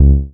低音合成器2个八度" B G0
描述：低音合成器八度单音G0
标签： 低音音符 八度 单一的 合成器
声道立体声